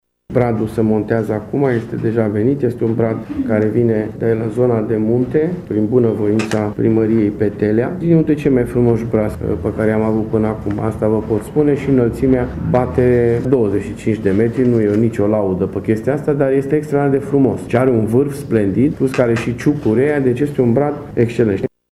Primarul municipiului Tîrgu-Mureş, Dorin Florea: